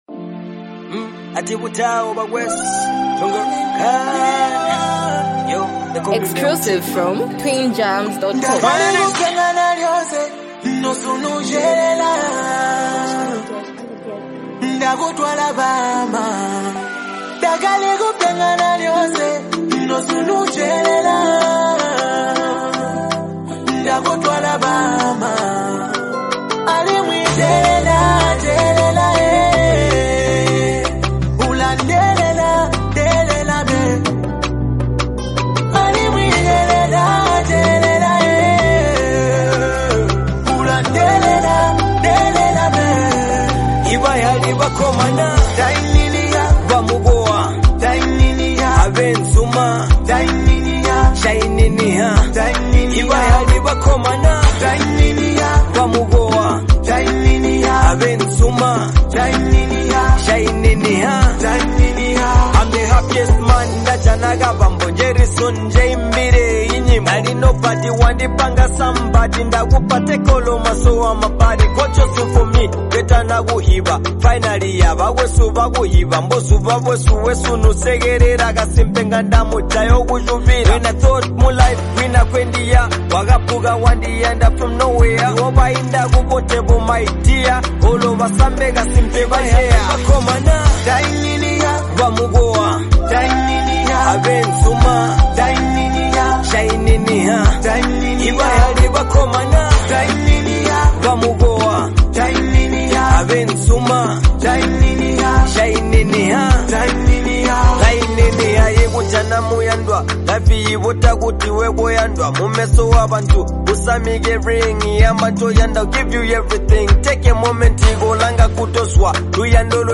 The song carries a romantic tone